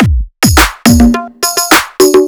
105 BPM Beat Loops Download